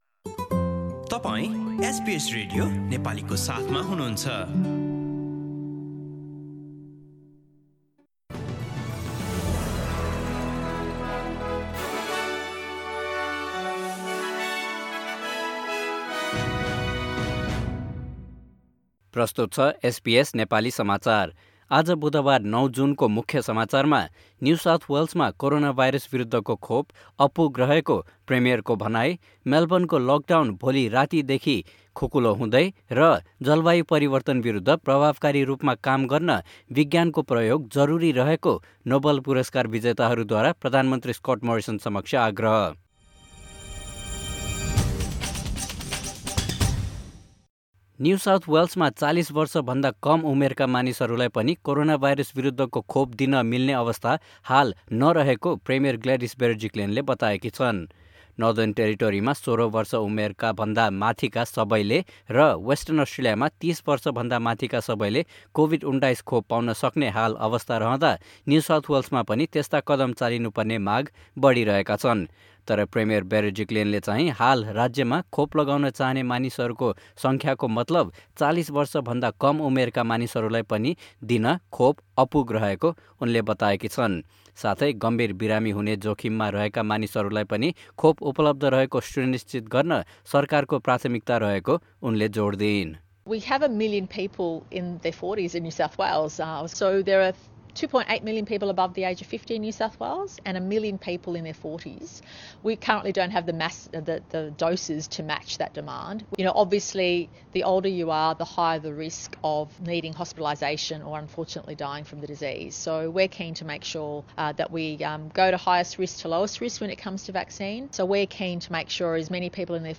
Listen to the latest news headlines from Australia in Nepali.